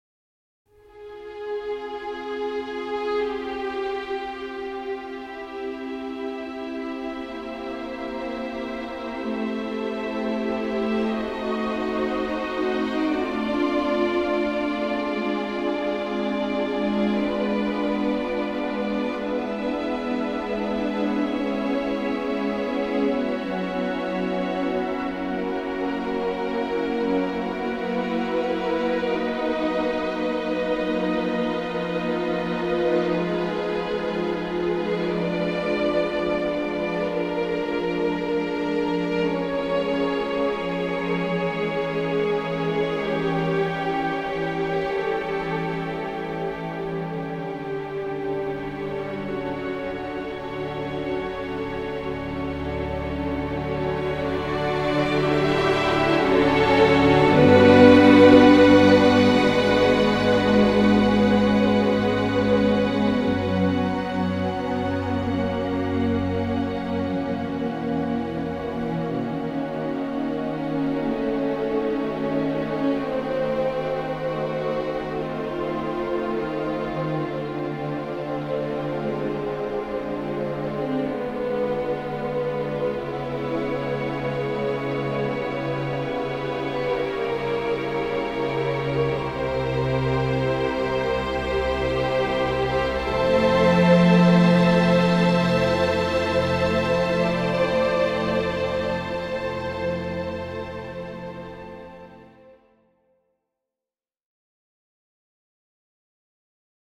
Slow Emotional Classical Strings NEW